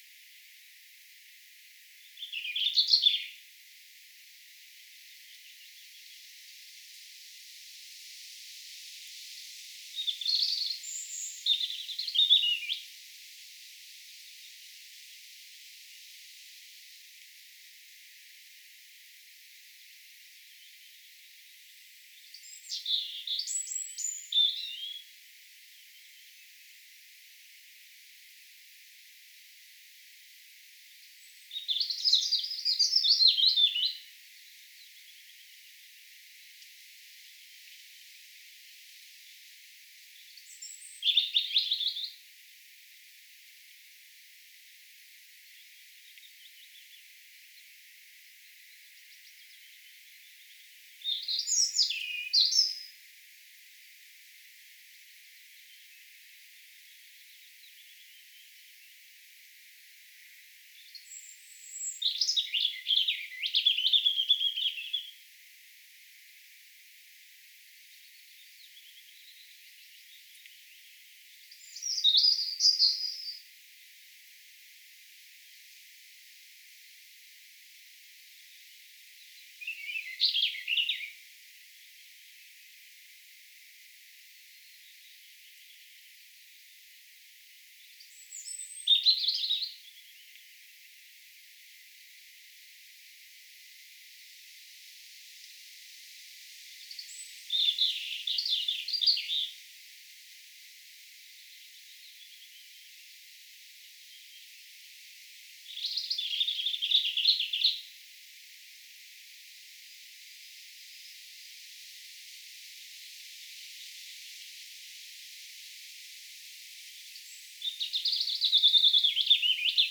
punarinnan laulua
Punarinnan lauluissa voi huomata sen
kuinka laulutapa muuttuu laulukauden kuluessa.
punarinta_laulaa_kesakuun_29..mp3